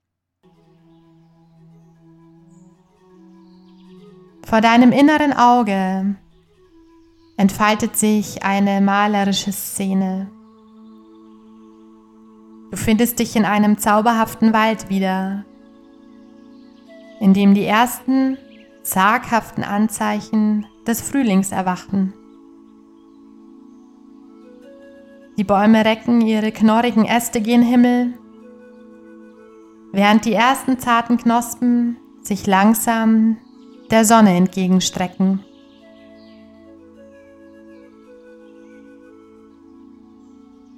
Diese geführte Meditation lädt Dich ein, Dich inmitten eines erwachenden Frühlingswaldes zu entspannen und die harmonische Kraft des Gleichgewichts zwischen Licht und Dunkelheit zu erfahren.